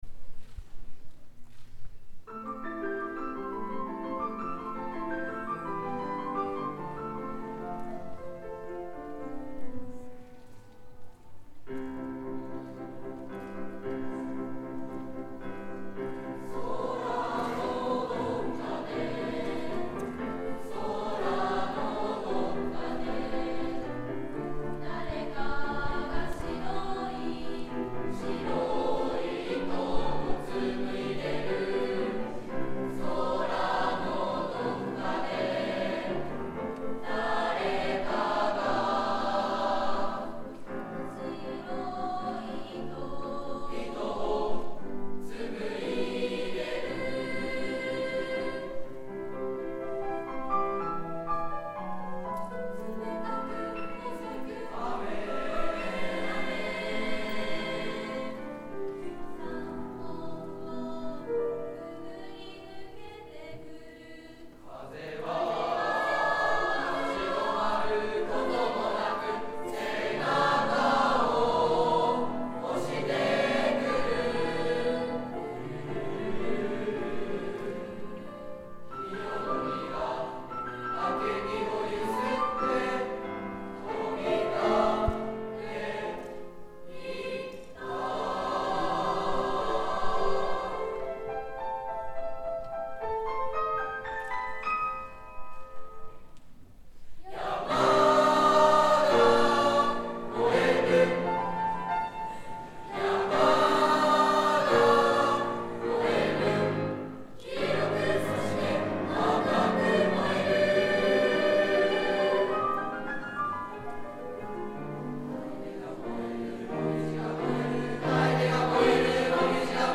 ３Ｆ 風のめぐるとき.mp3 ←クリックすると合唱が聴けます